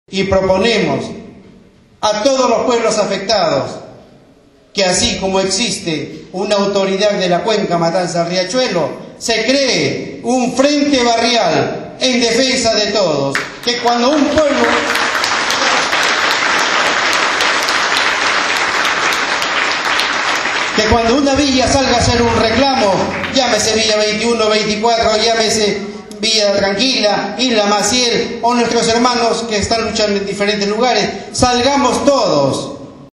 El sábado 18 de agosto en la escuela Lafayette de Barracas se realizó un encuentro por el conflicto por la relocalización de las familias que viven en el camino de sirga.